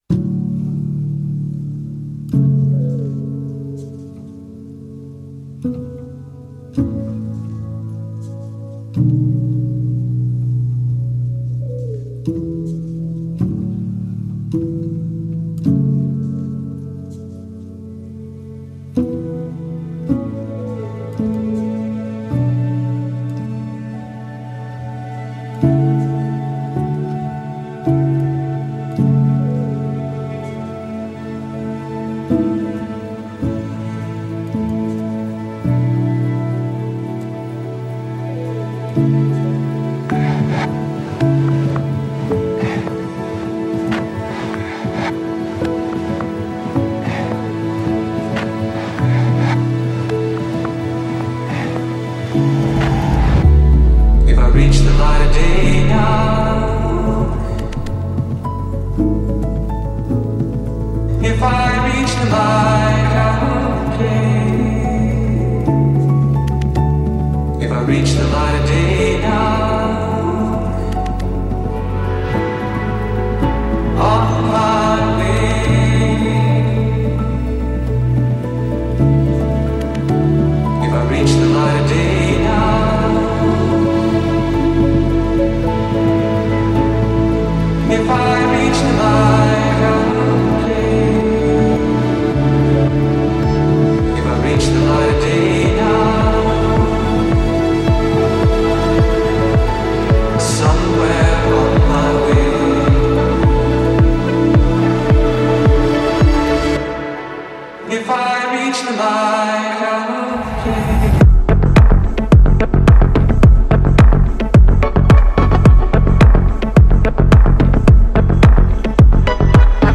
موسیقی الکترونیک
موسیقی اینسترومنتال